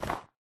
dig / snow4